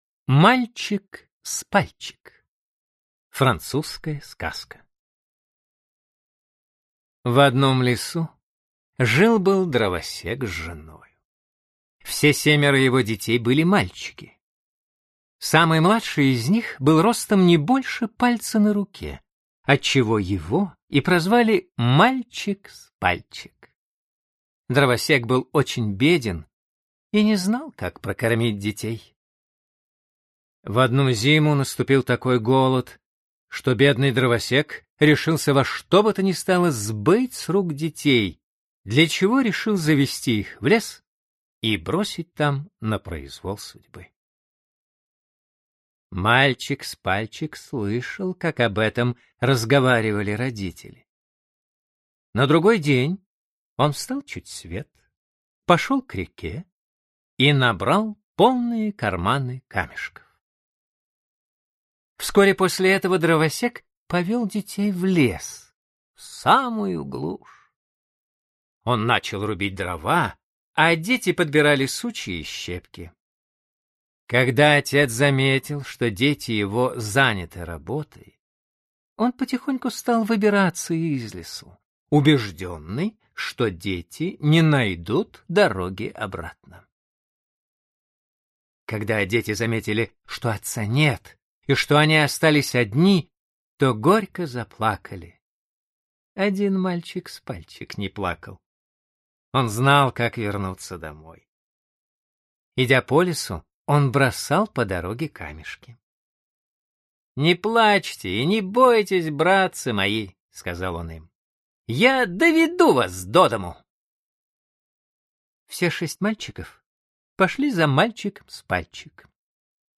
Аудиокнига Золотая книга сказок. Французские сказки | Библиотека аудиокниг